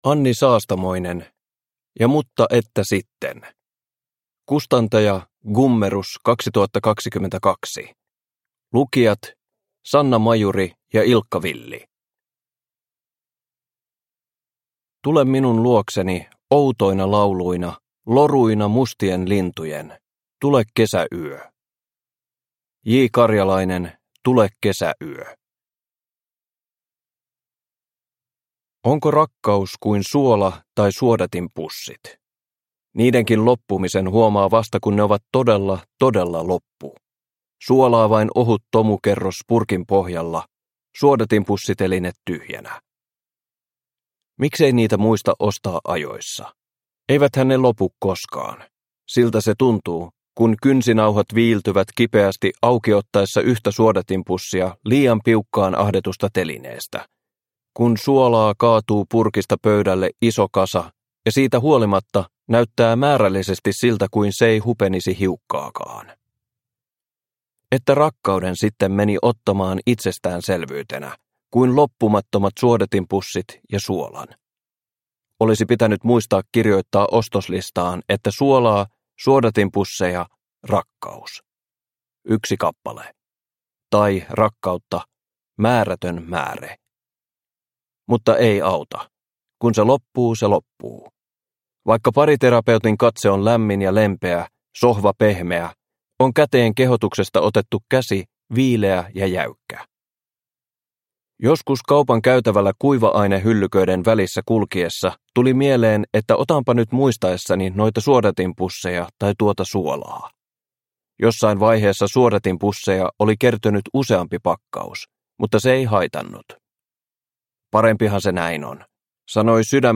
Ja mutta että sitten – Ljudbok – Laddas ner